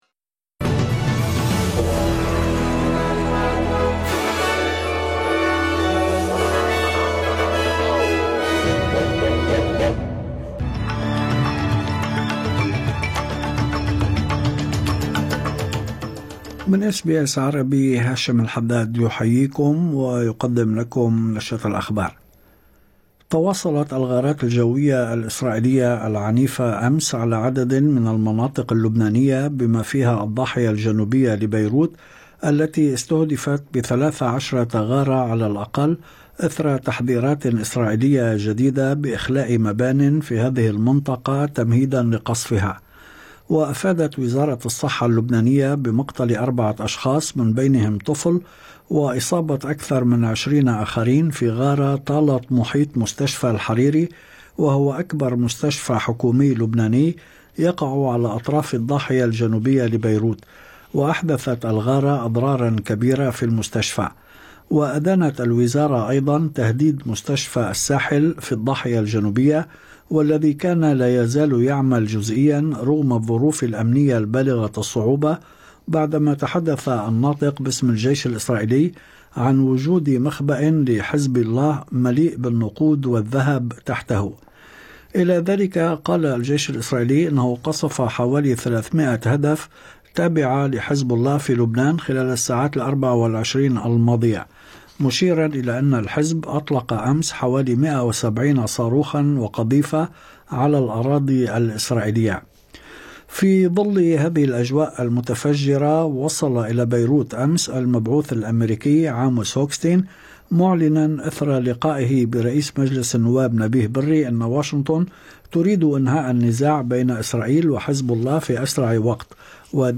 نشرة أخبار الظهيرة 22/10/2024